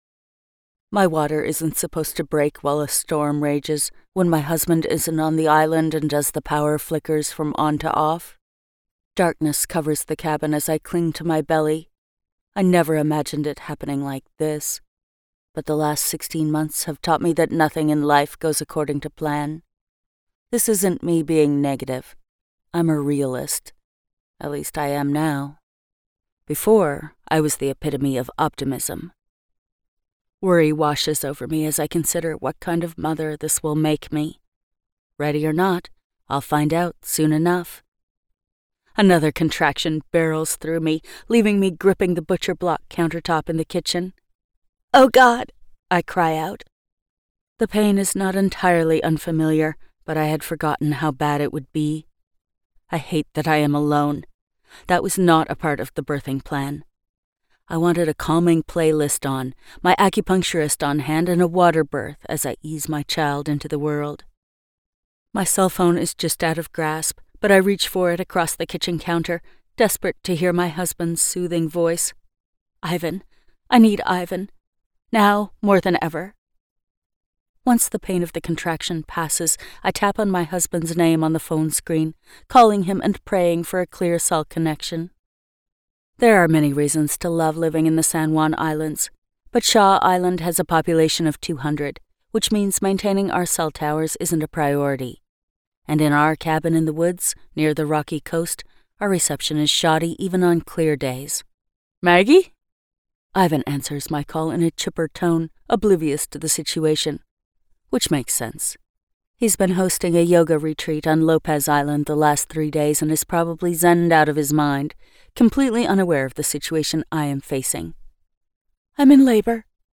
Not My Baby - Vibrance Press Audiobooks - Vibrance Press Audiobooks